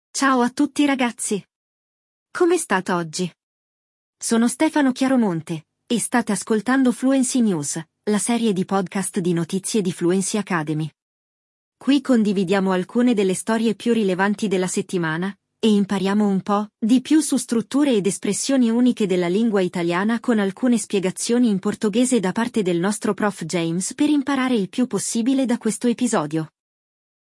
Ao longo do episódio, nós também adicionamos explicações em português das coisas que achamos que precisam de mais atenção, assim você não perde nenhum detalhe!